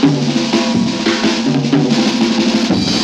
JAZZ BREAK 4.wav